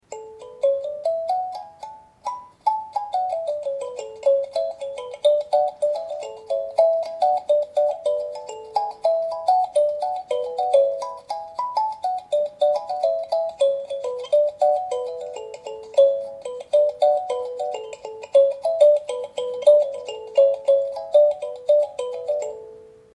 Kalimba 9 lames
Inspirée d’un modèle Touareg, cette sanza  appellée kalimba comporte 9 lames en acier accordées sur un résonateur métallique, avec une table en bois (diverses essences, noyer, érable, chêne…)  pour une étendue de 1 octave dans le mode de do (C).
un petit bijou au son cristallin et puissant pour sa taille
kalimba.mp3